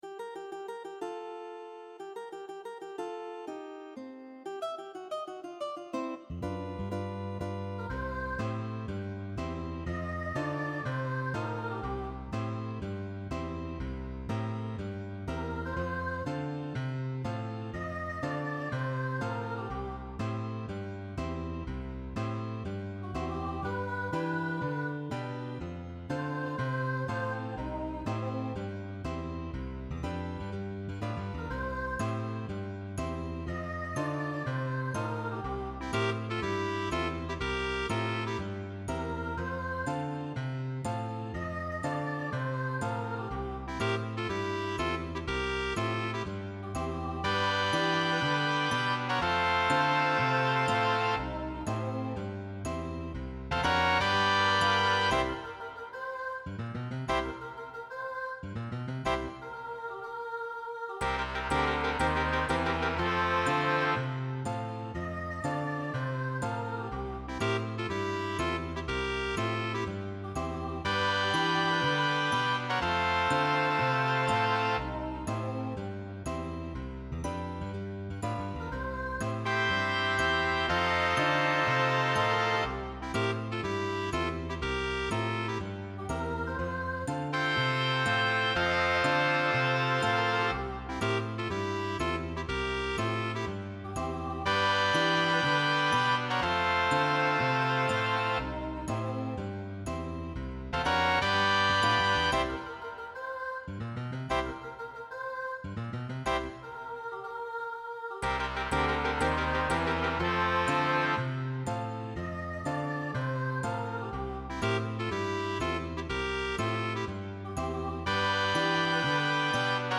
ORCHESTRA DIDATTICA